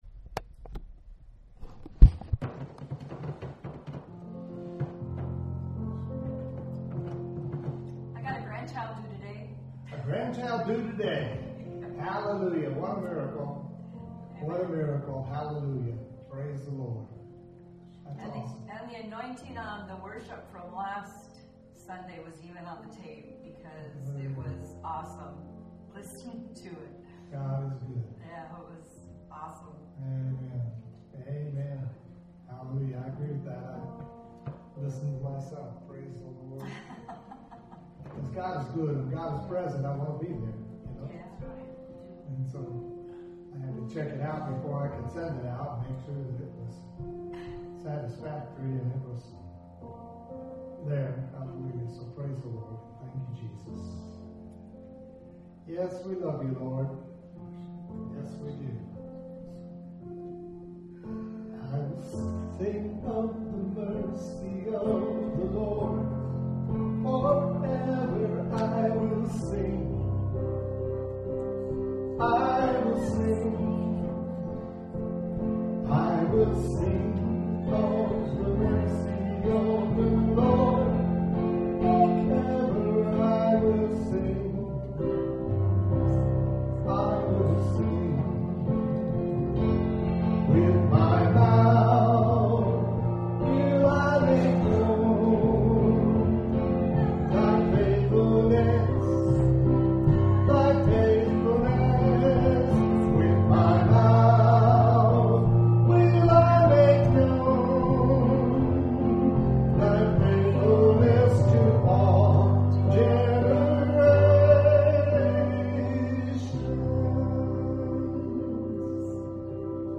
WORSHIP 68.mp3